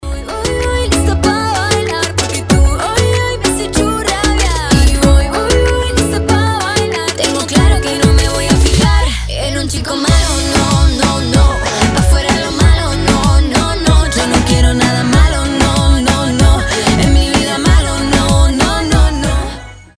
• Качество: 320, Stereo
ритмичные
женский вокал
заводные
озорные
Reggaeton
Latin Pop